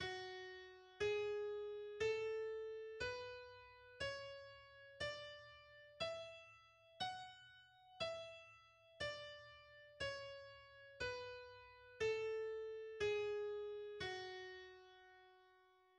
F-sharp minor is a minor scale based on F, consisting of the pitches F, G, A, B, C, D, and E. Its key signature has three sharps.
The F-sharp natural minor scale is: